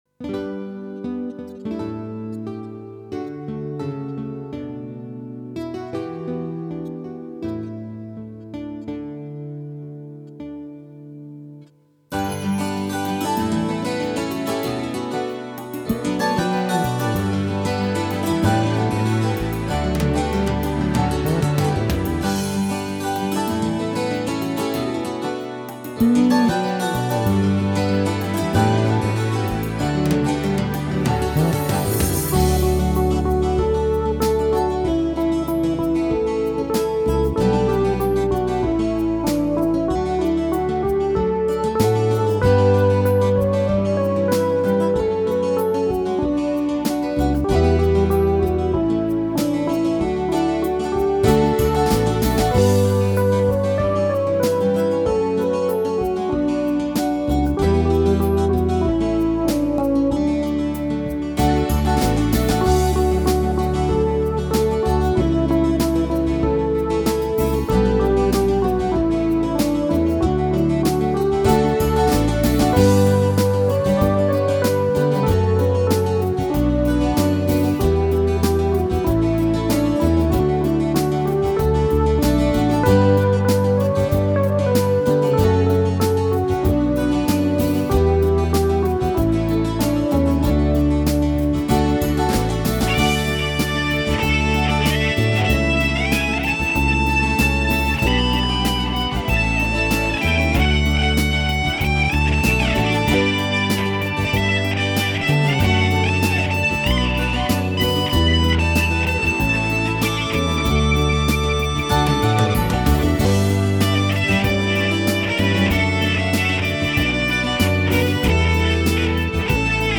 О маме(минус)